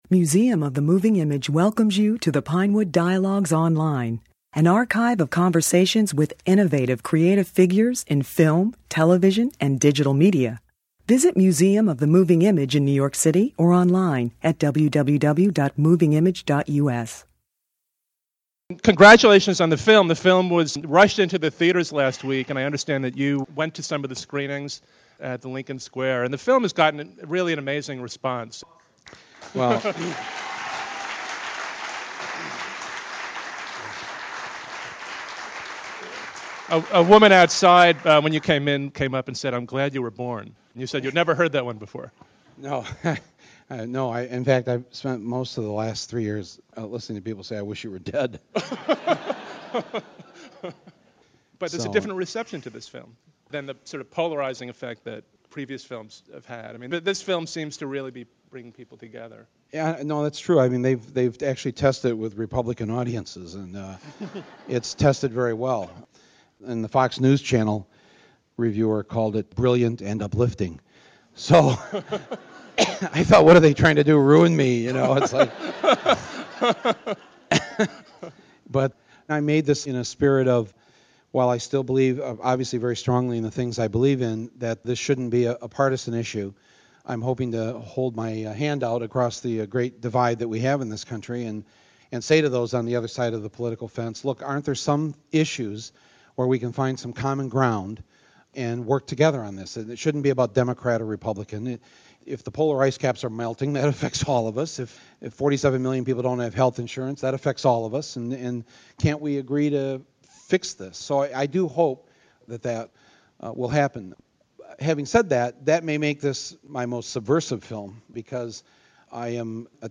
Sicko , his widely acclaimed, no-holds barred attack on the American healthcare system, uses the issue of healthcare to explore larger questions about what kind of country America is. In this discussion presented by the Museum of the Moving Image in collaboration with Variety , Moore spoke passionately about the many questions raised by his film.